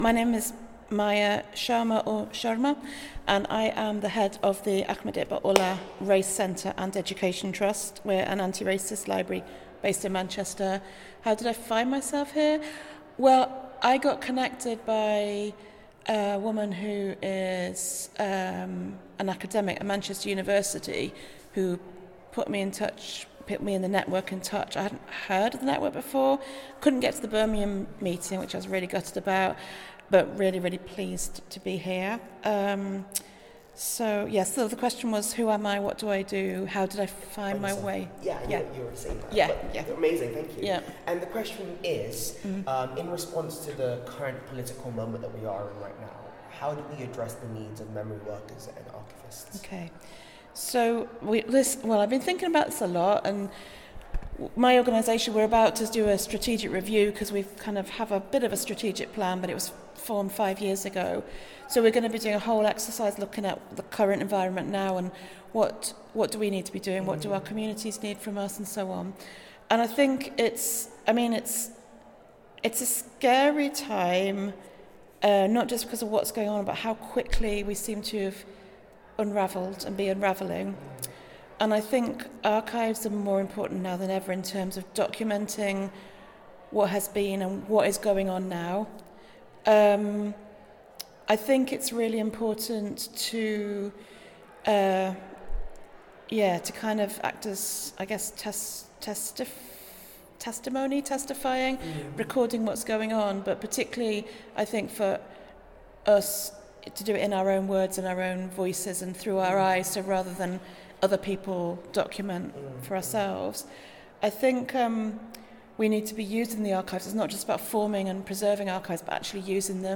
The following audio reflections, recorded during the Brighton convening, feature members of the UK Community of Practice addressing the urgent question: How can we use the current political climate we’re in right now to shape the needs of memory work?